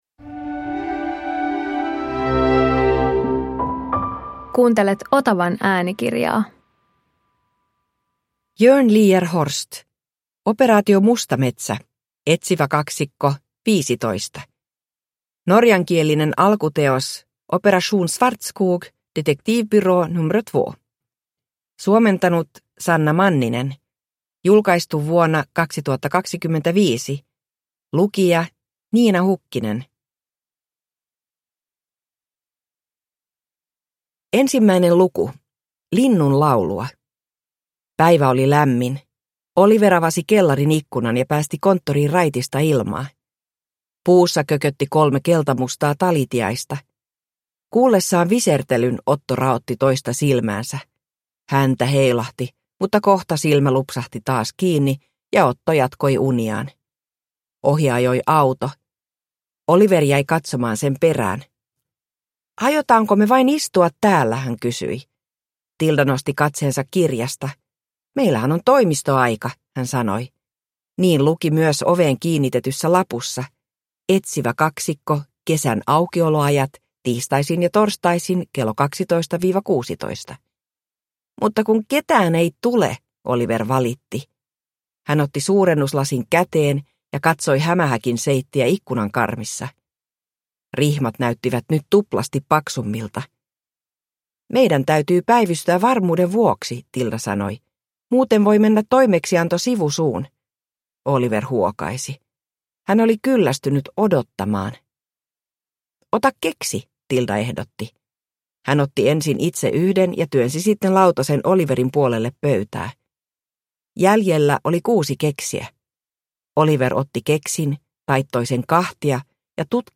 Operaatio Mustametsä – Ljudbok